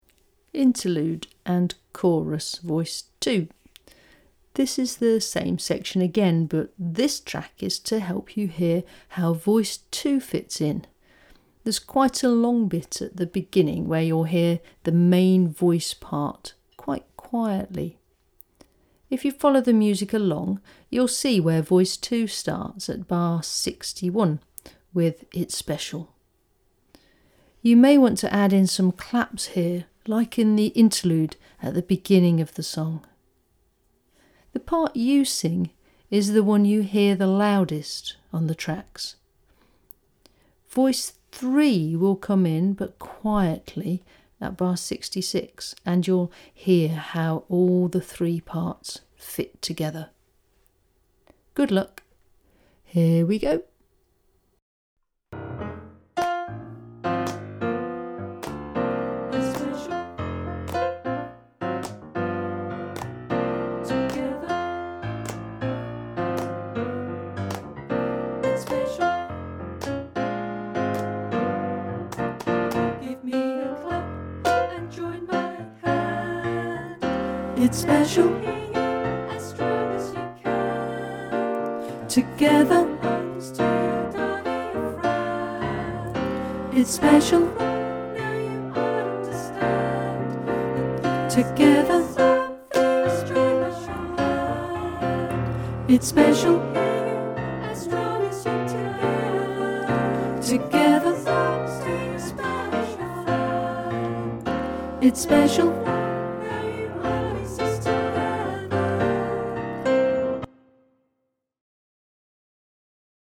The other tracks will help you learn each section before you sing it all through with the full-length track.
interlude & chorus voice 2